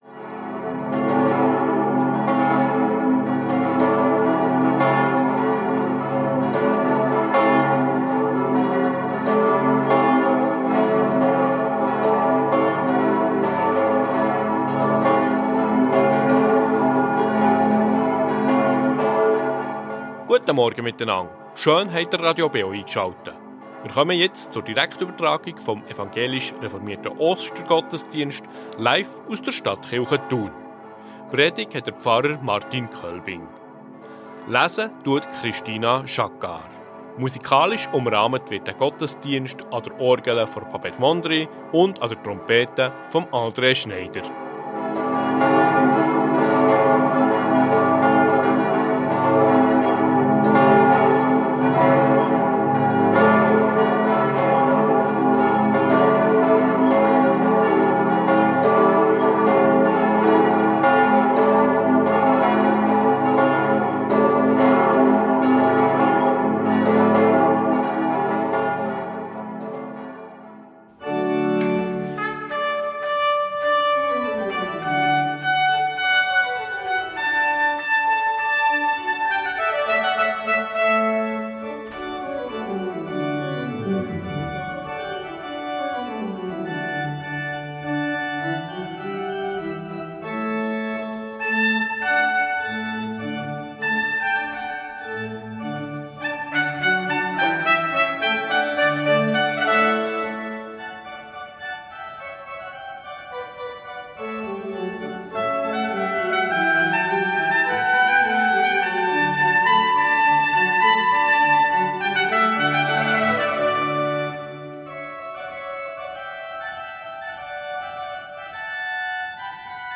Stadtkirche Thun ~ Gottesdienst auf Radio BeO Podcast